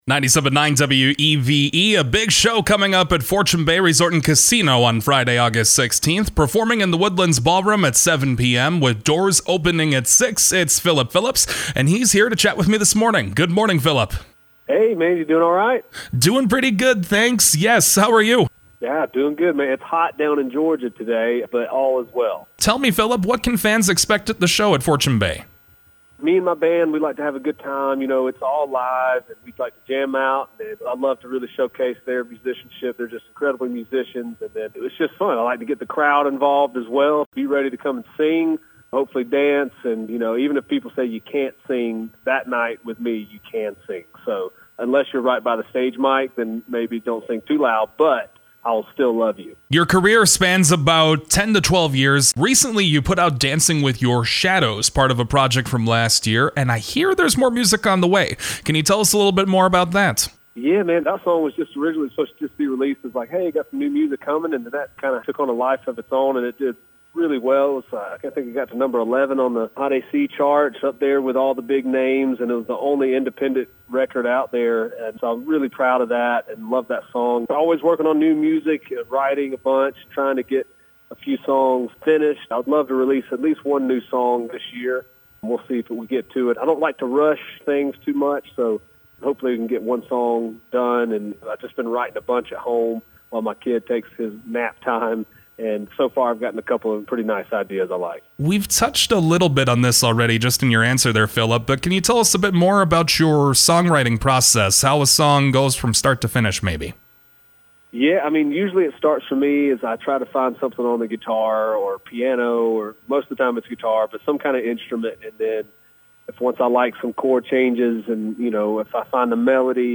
Interview with Phillip Phillips
weve-philip-phillips-interview-for-air.mp3